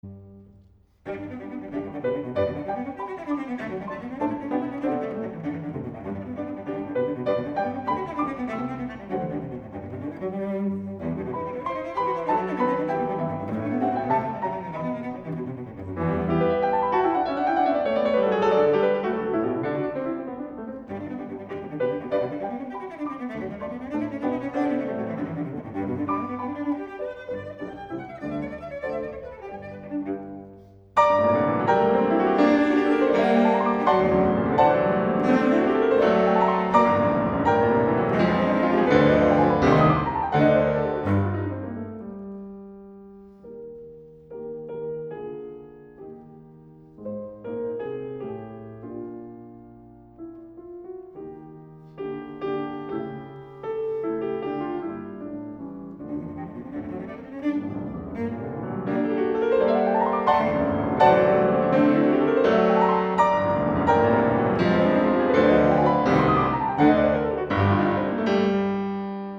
古典音樂